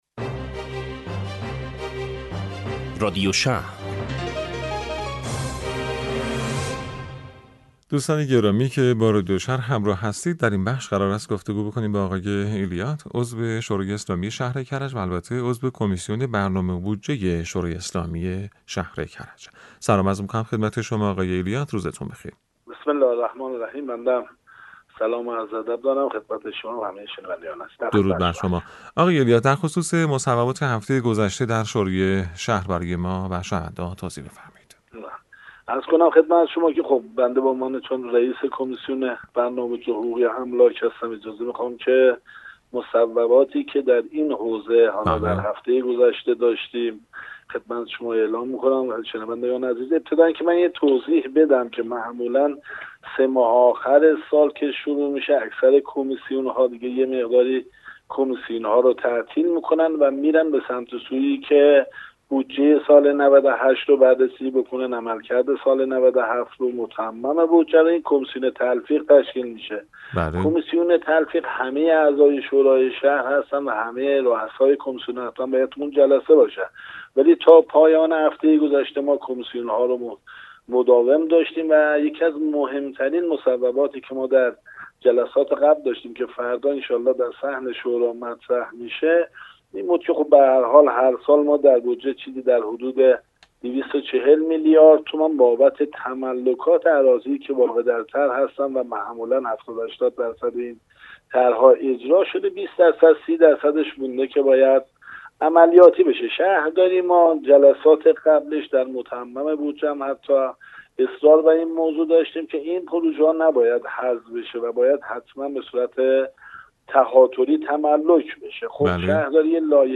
گفت وگوی رادیو شهر با عضو شورای شهر کرج
فرج الله ایلیات، عضو شورا و رییس کمیسیون برنامه بودجه، حقوقی و املاک شورای شهر کرج با رادیو اینترنتی مدیریت شهری شهرداری کرج در خصوص تکمیل پروژه ها و اولویت در تملک املاک گفت وگو کرد.